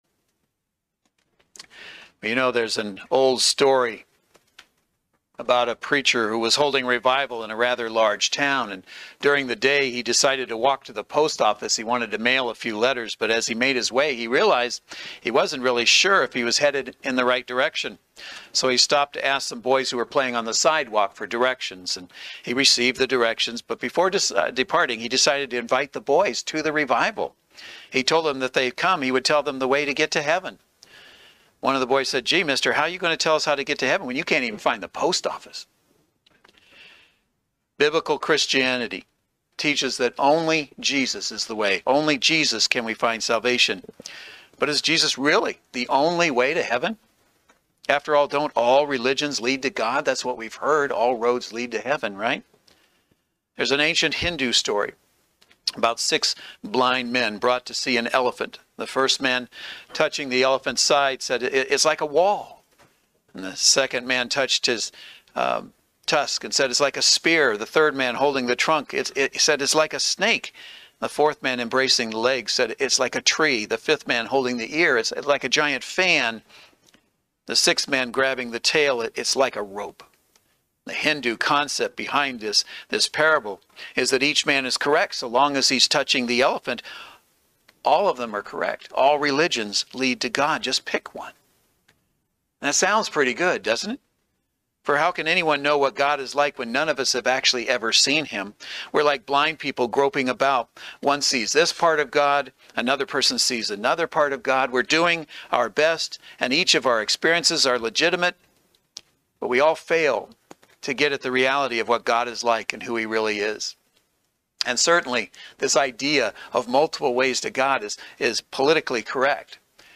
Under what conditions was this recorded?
Service Type: Saturday Worship Service The greatest danger to Christians today comes not from outside the Church – but from within.